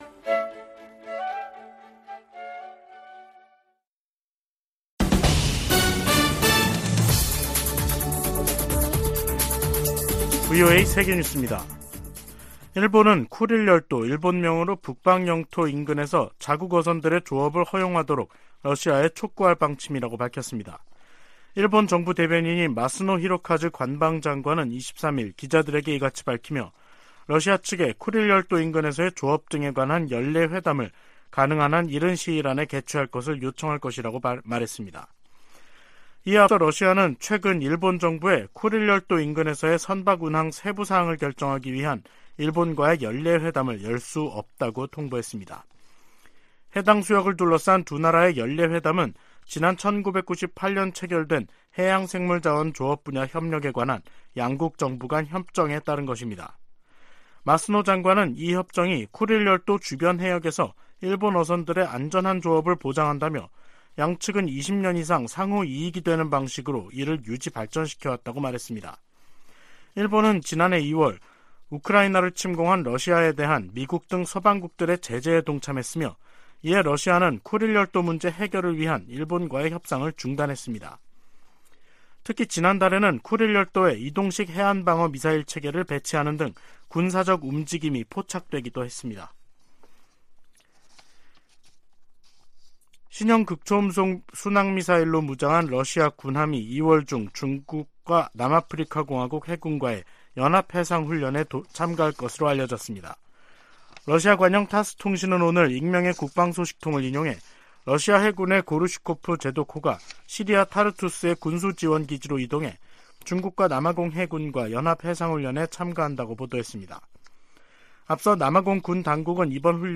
VOA 한국어 간판 뉴스 프로그램 '뉴스 투데이', 2023년 1월 23일 3부 방송입니다. 백악관이 북한과 러시아 용병그룹 간 무기 거래를 중단할 것을 촉구하고, 유엔 안보리 차원의 조치도 모색할 것이라고 밝혔습니다. 미 태평양공군은 한국 공군과 정기적으로 훈련을 하고 있으며, 인도태평양의 모든 동맹, 파트너와 훈련할 새로운 기회를 찾고 있다는 점도 강조했습니다.